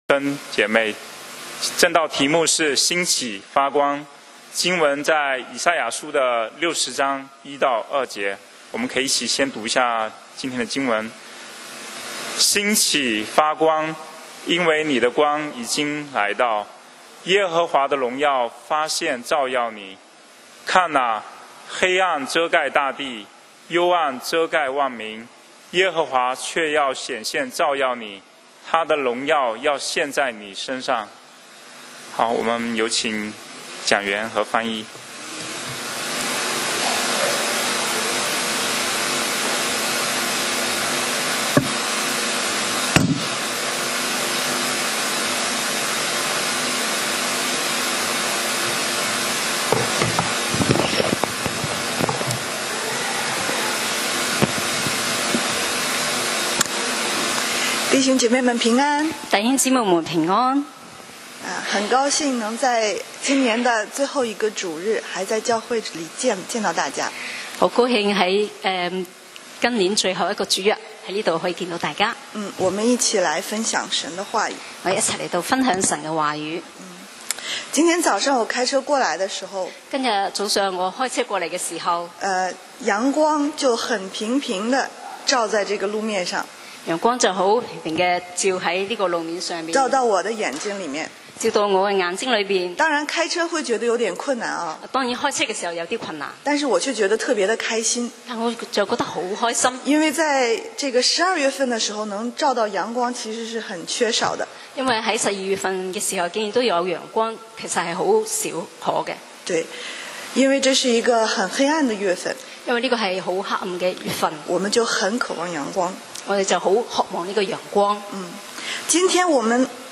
講道 Sermon 題目 Topic： 兴起，发光！